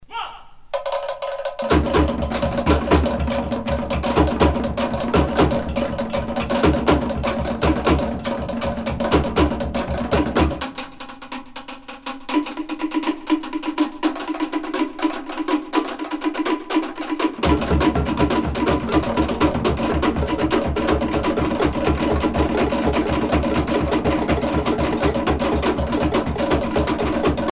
Groupe folklorique